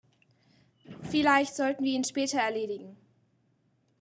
REMARKABLY NEATO GERMAN PASSAGES